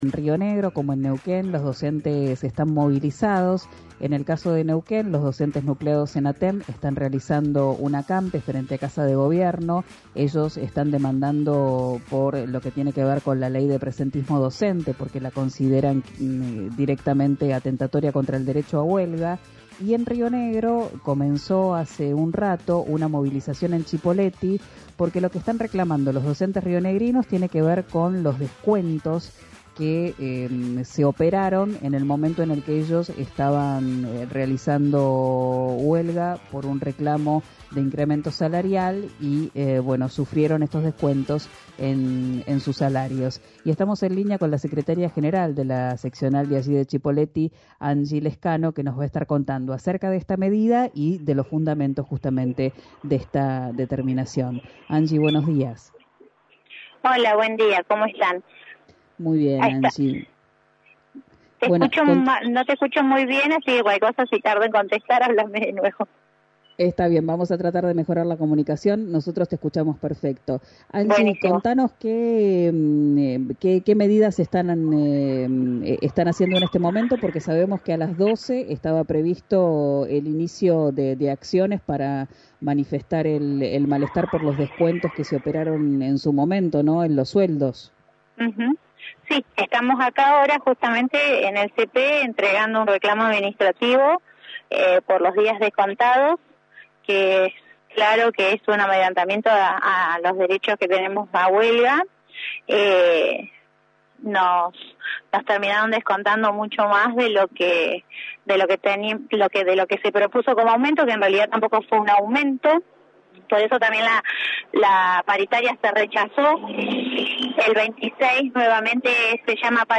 En dialogo con RÍO NEGRO RADIO